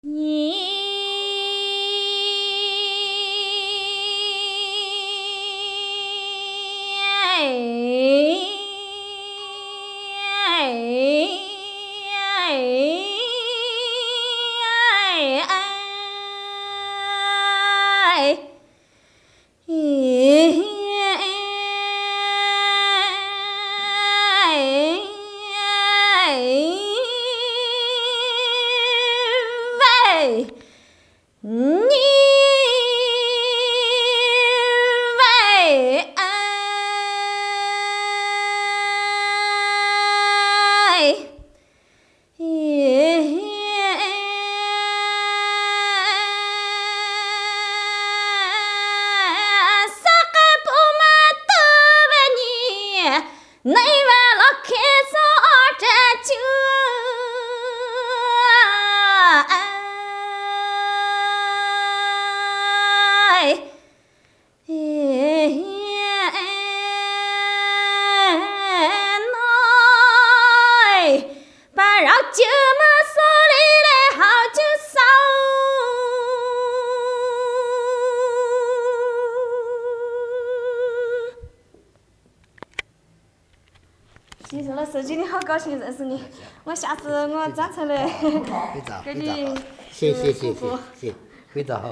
女声云水腔.mp3